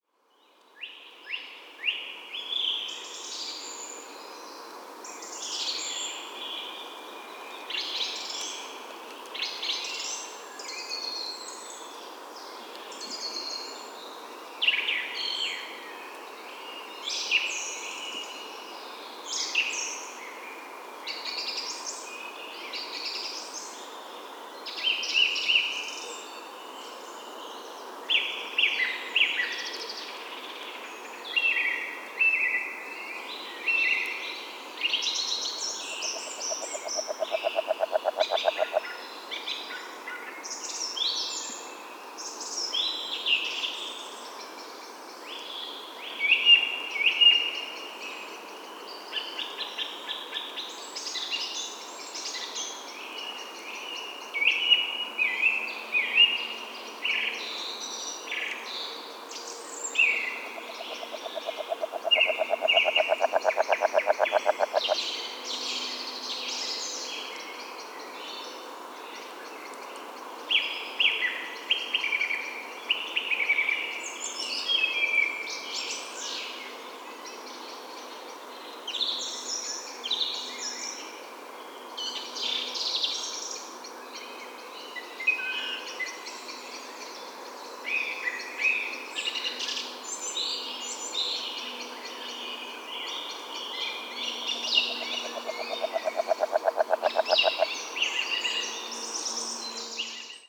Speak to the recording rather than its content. north-western Saxony, Germany, Telinga parabolic reflector